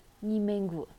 [ ʔa˥˩ ]